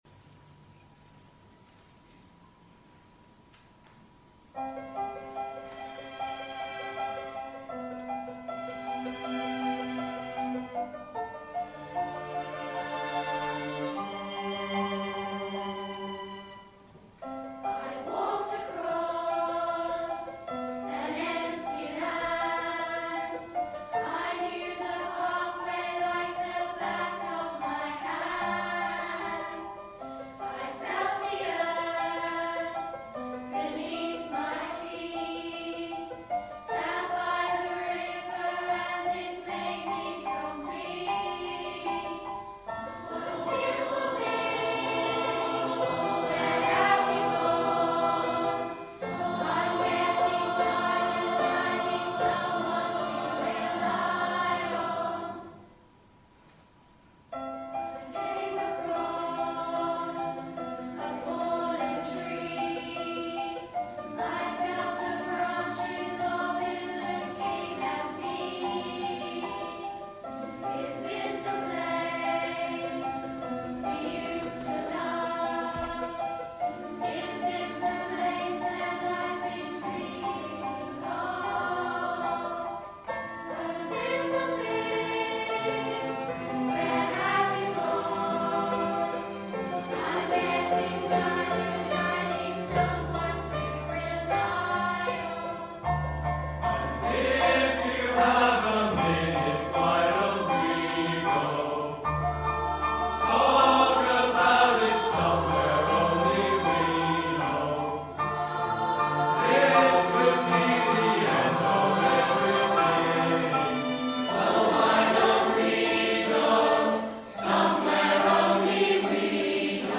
Performed by Take Note, Coro, Melodic Minors and the Orchestra